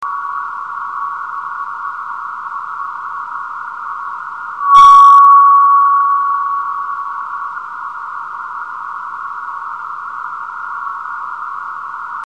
suono prodotto da una meteora iperdensa breve